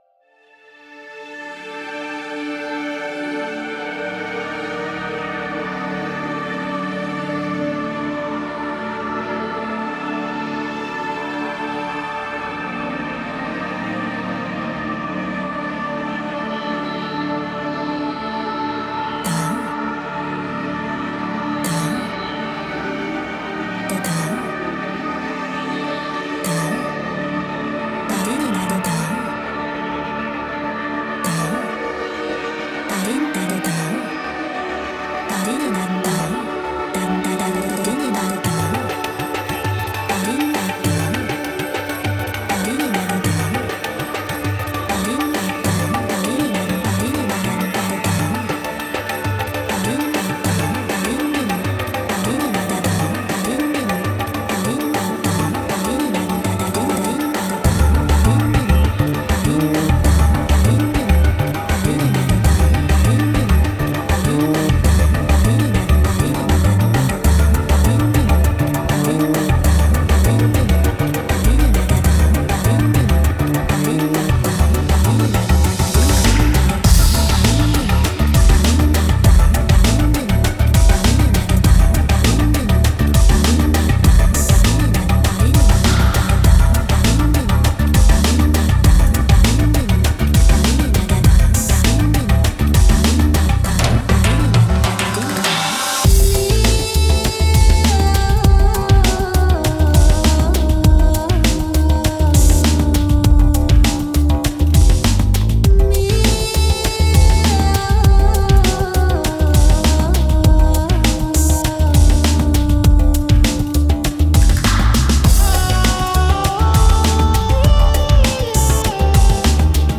Genre: Psychill, Downtempo.